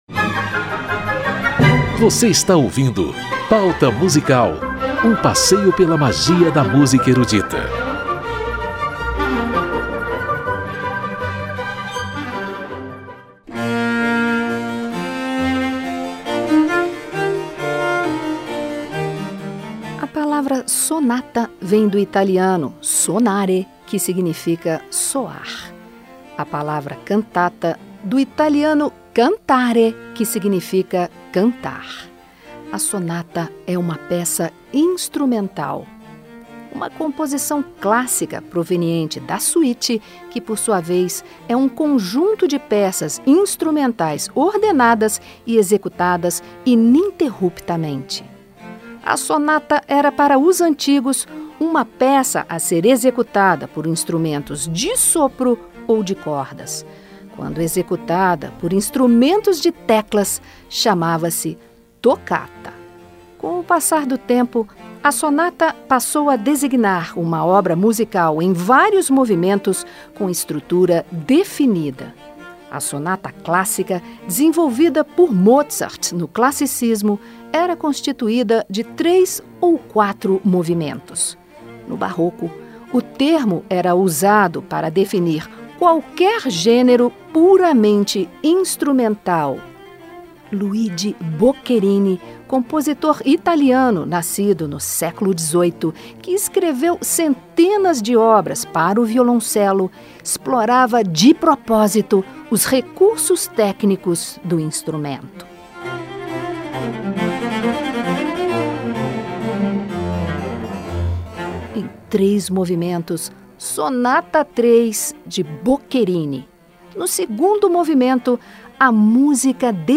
Talento brasileiro em interpretação musical de obras barrocas!
sonatas para cravo e 2 violoncelos
concertos para 2 violinos e orquestra de cordas